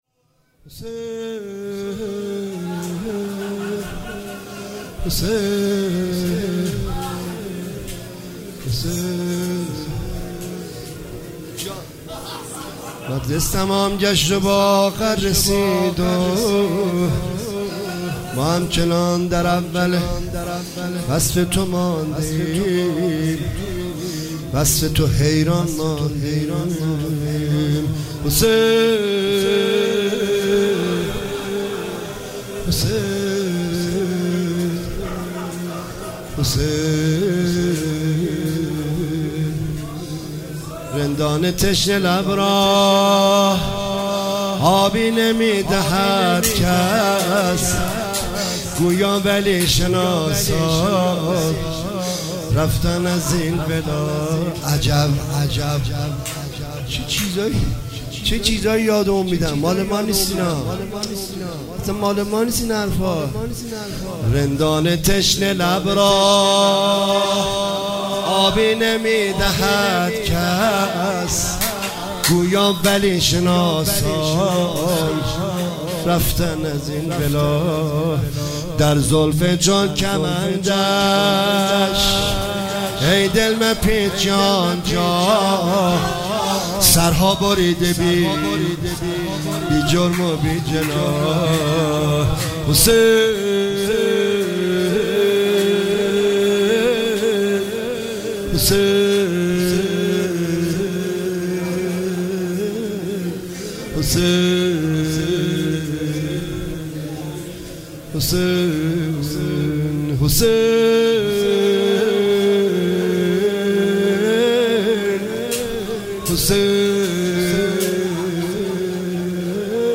هیئت هفتگی 26 اردیبهشت 1404